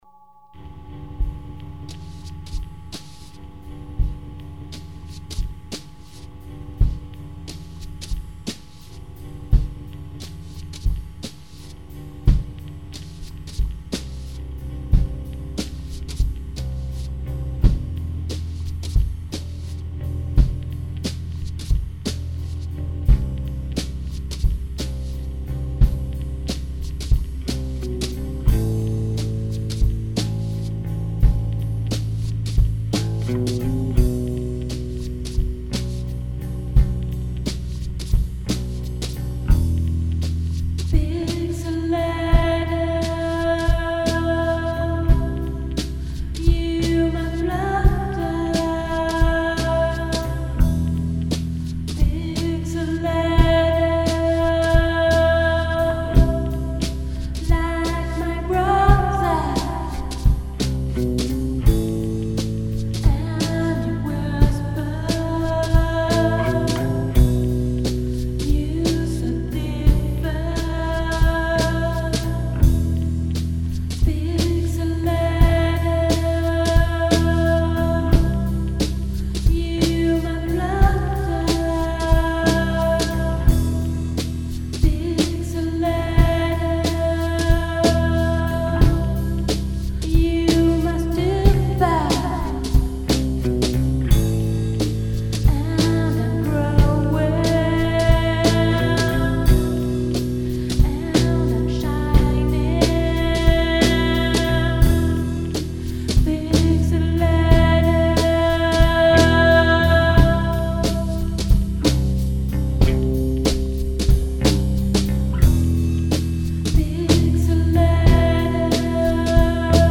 vocals
drums
both playing bass